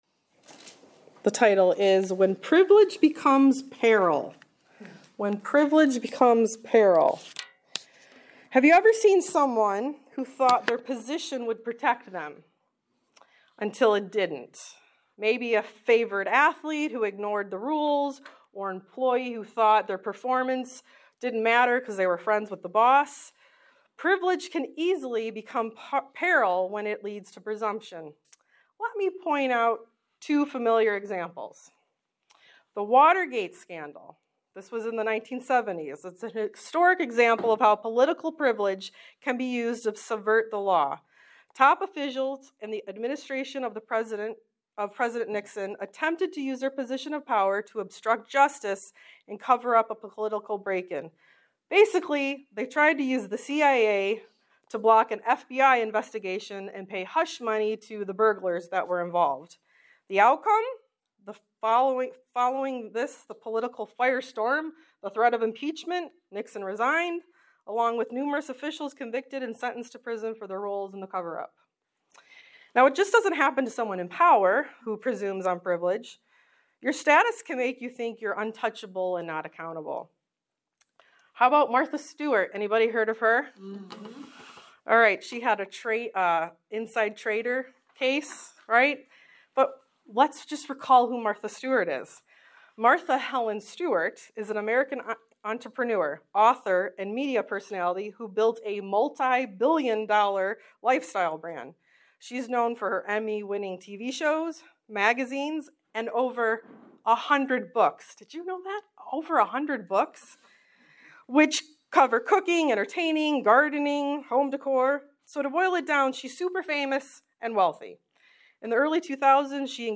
Service Type: Women's Bible Study